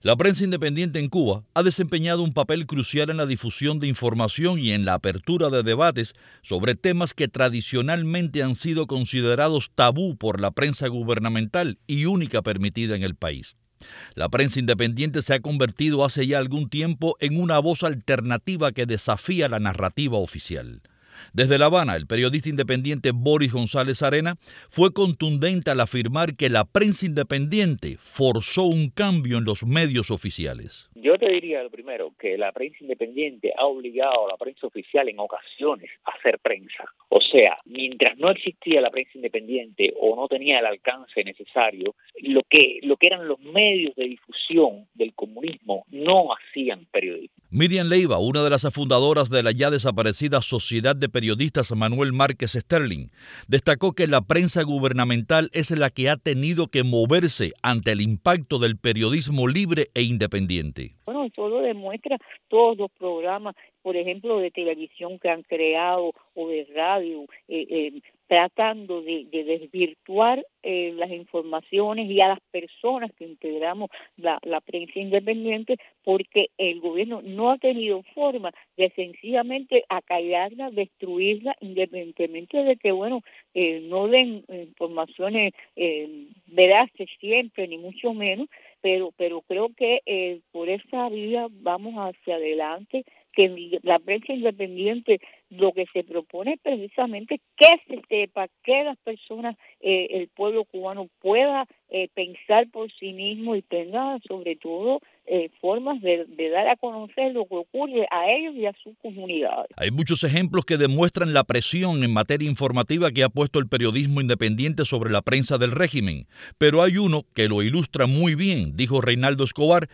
Testimonios de periodistas en la isla a propósito del Día Mundial de la Libertad de Prensa